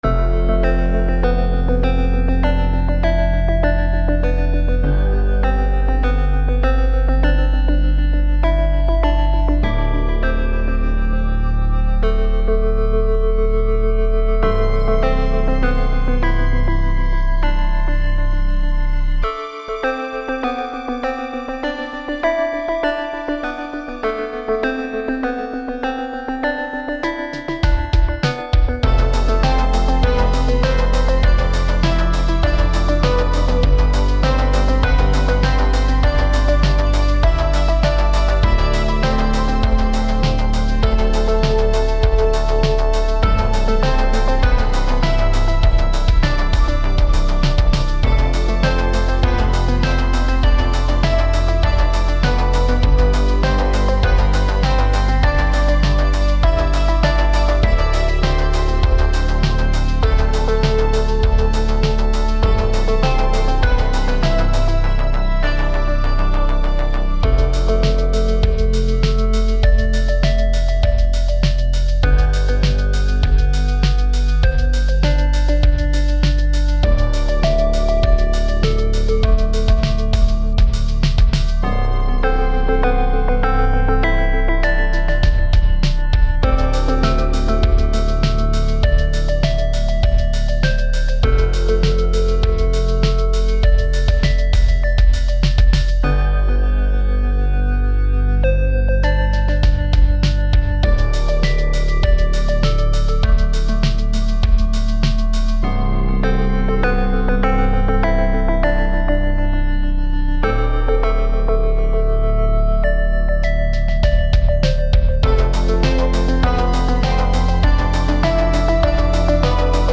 I brought it back for a bit of modern synth-wave type vibe
synthwave
It has this tension in it that I love, and it drives forward.